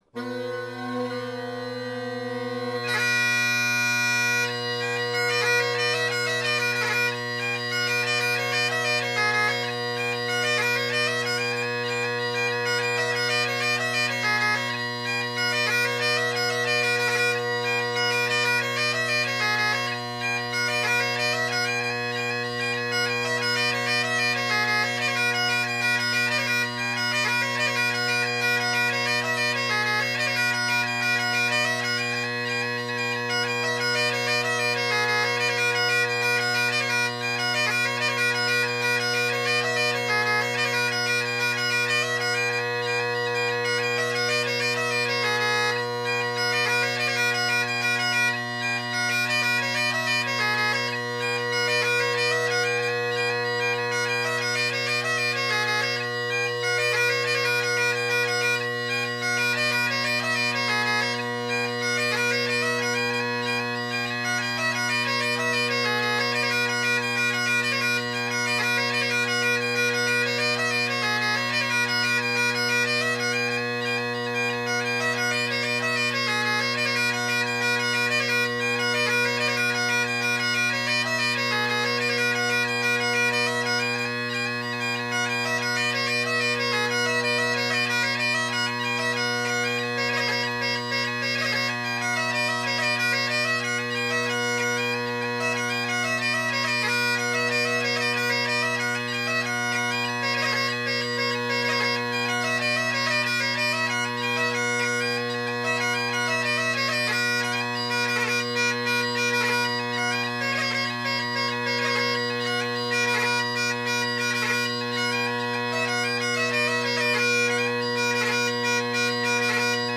Below is a set of tunes I used to play with some Irish session musicians as they kindly played along with me on my “A” Scottish smallpipes; though I’ve arranged High Reel differently now.